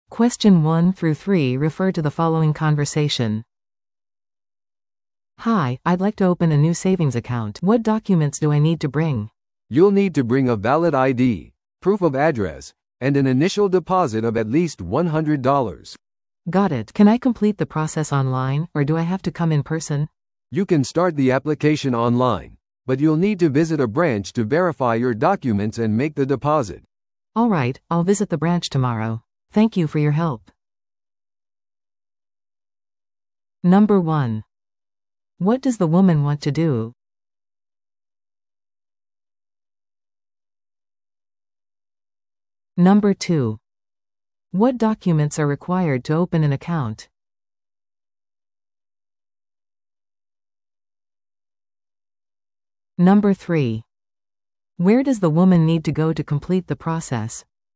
PART3は二人以上の英語会話が流れ、それを聞き取り問題用紙に書かれている設問に回答する形式のリスニング問題です。